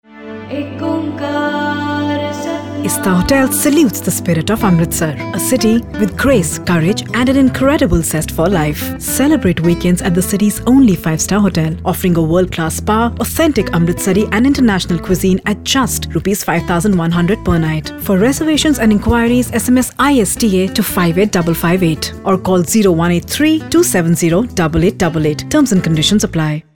Indian, Hindi, Punjabi, Neutal Accent, Friendly, Sexy, Conversational
Sprechprobe: Sonstiges (Muttersprache):